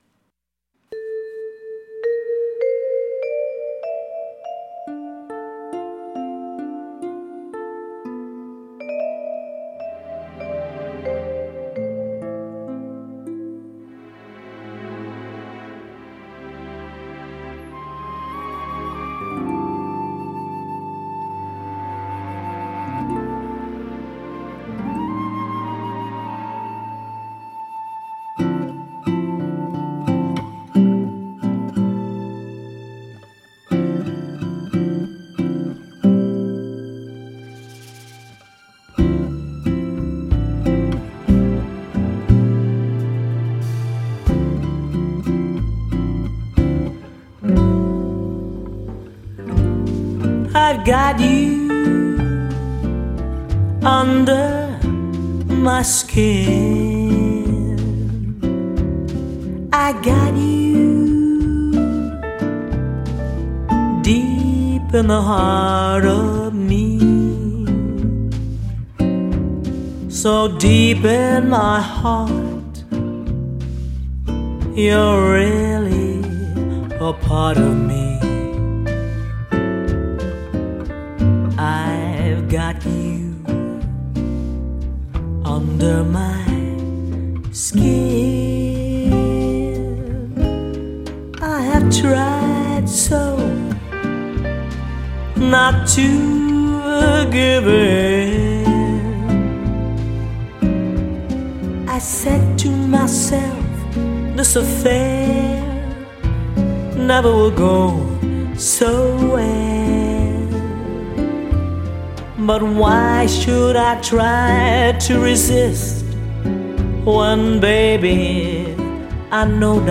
American Jazz Standard songs
third track of her Jazz Standards CD